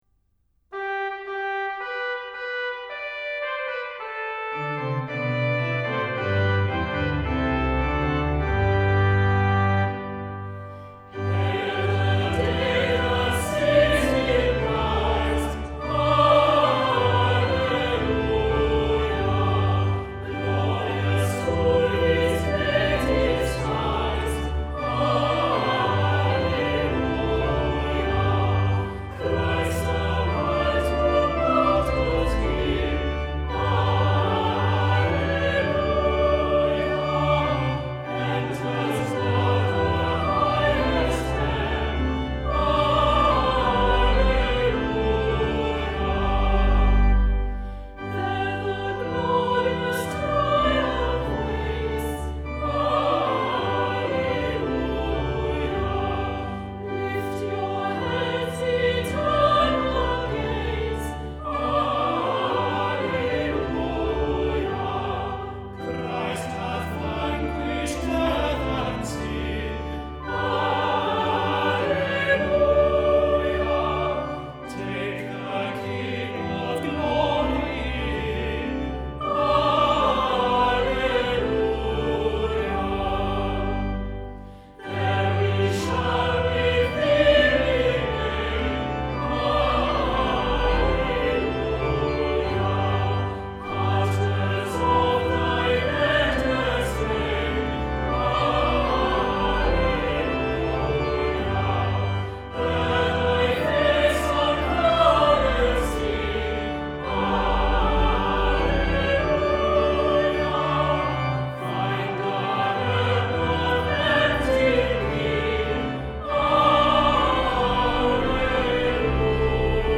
Opening Hymn Hail the day that sees him rise,